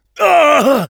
Voice file from Team Fortress 2 French version.
Engineer_painsevere03_fr.wav